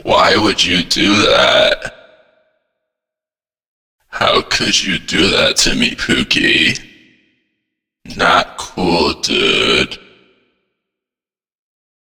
File:Figure crucified voicelines.ogg - DOORS Wiki